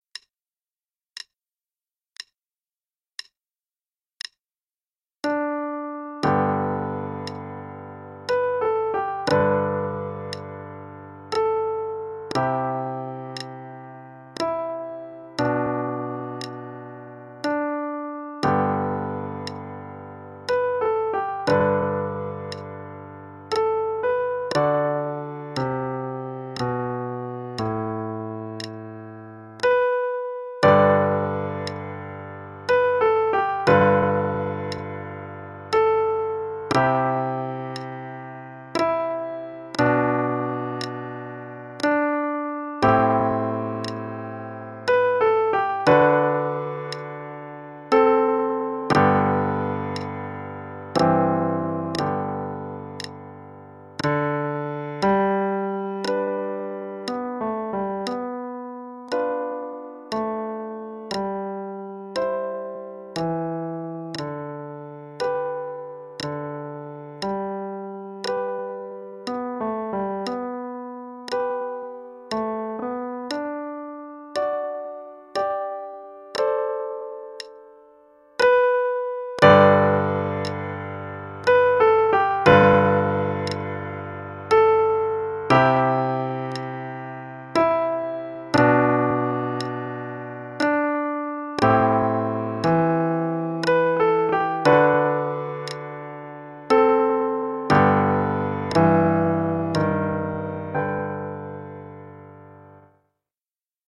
Traditional Melody:
Level 3A - page 28_Amazing Grace (both hands, qn=60).mp3